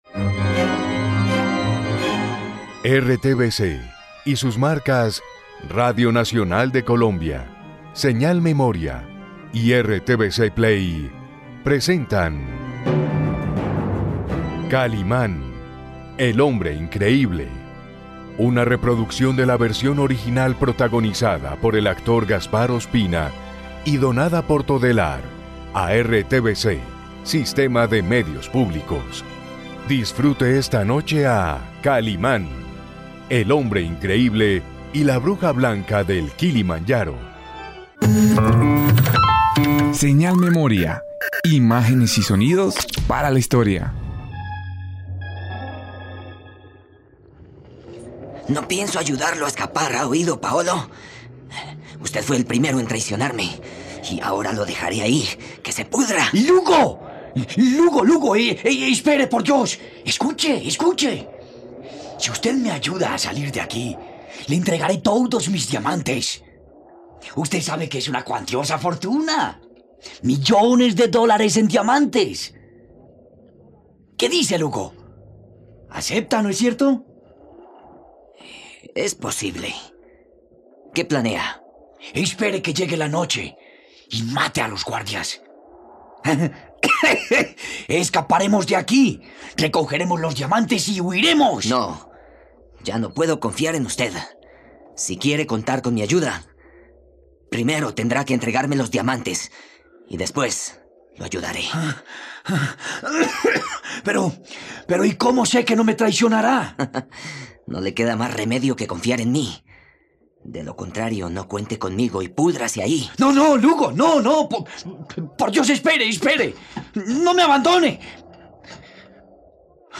¡Descubre esta radionovela gratis por RTVCPlay!